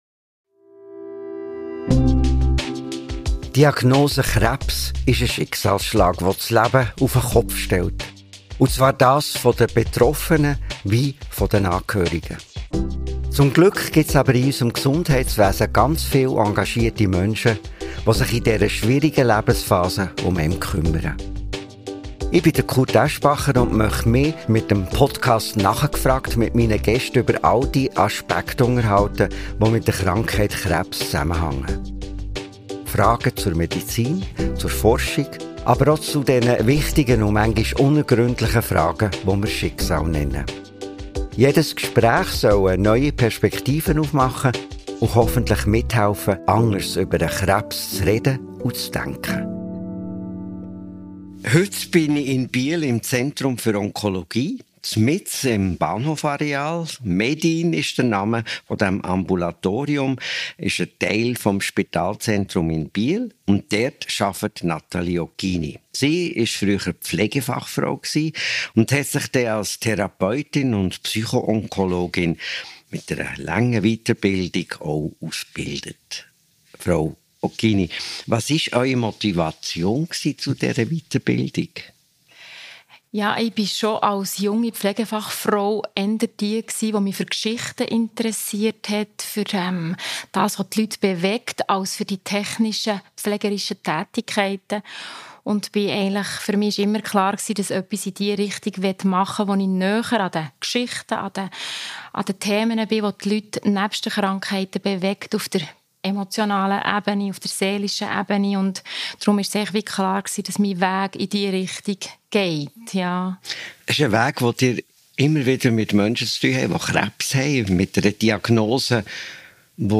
Hat Ihnen dieses Gespräch gefallen und möchten Sie weitere hören?